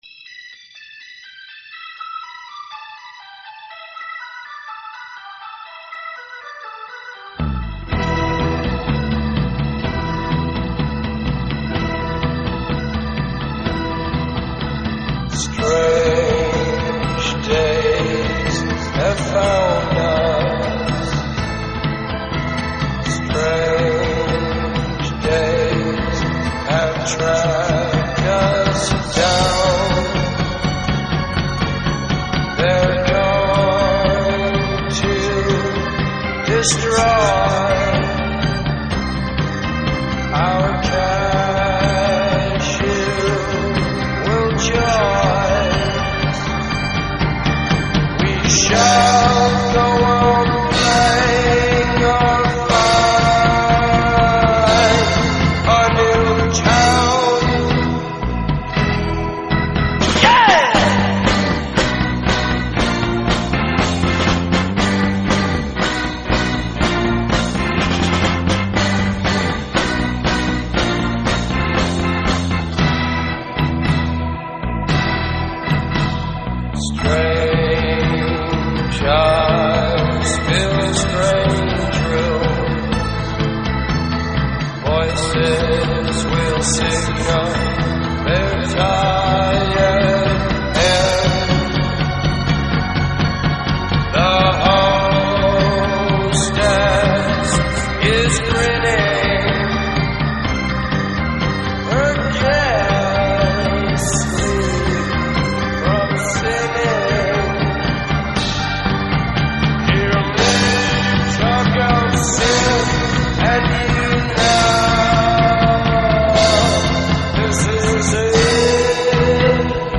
آلبوم کلاسیک پراگرسیو راک